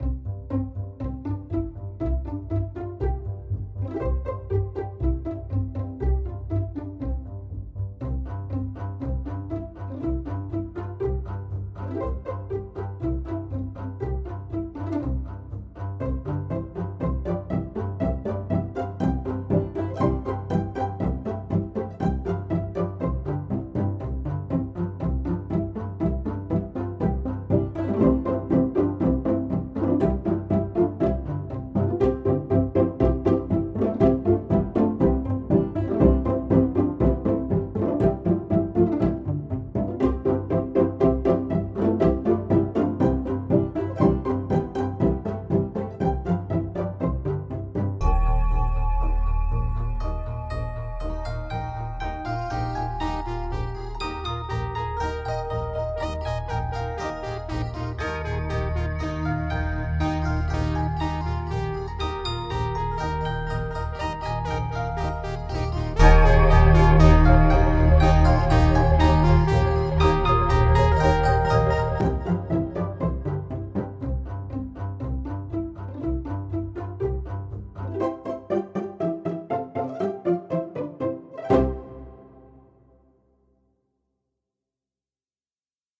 I’ve always been interested in digital music creation and have done a few projects here and there. One of my experiments was with Row, Row, Row Your Boat which you can hear by clicking above.